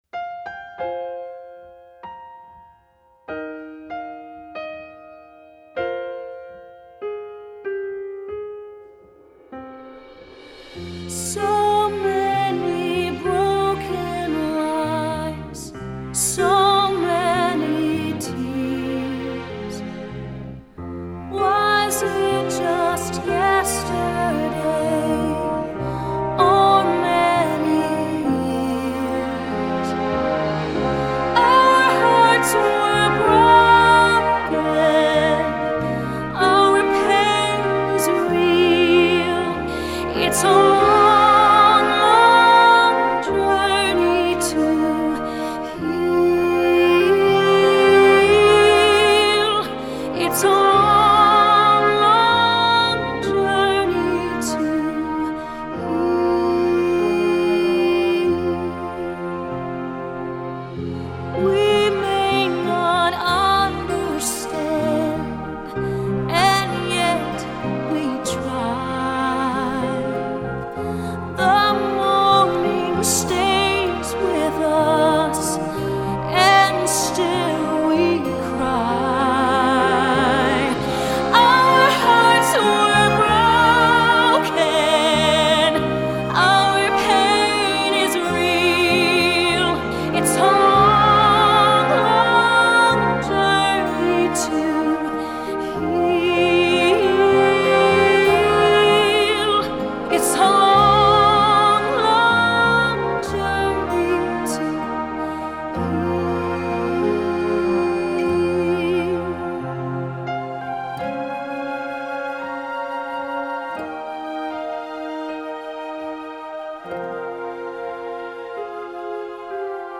The poignant song